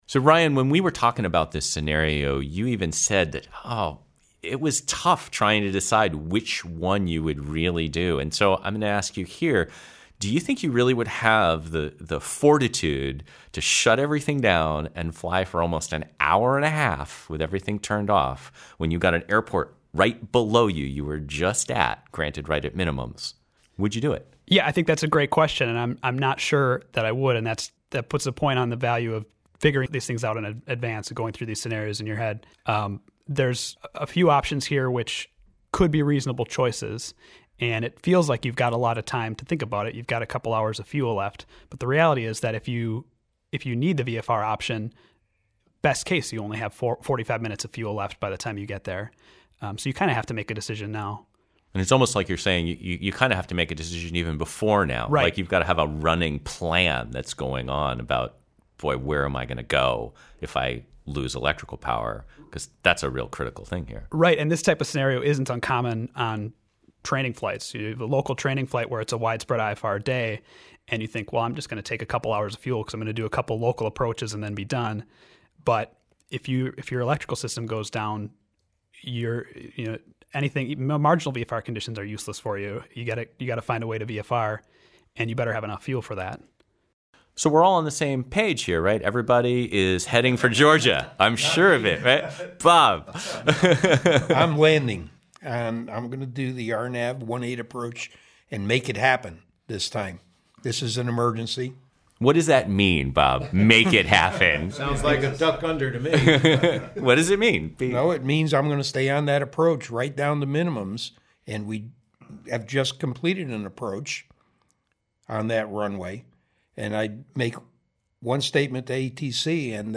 Load_shedding_Over_Deadman_Bay_rountable.mp3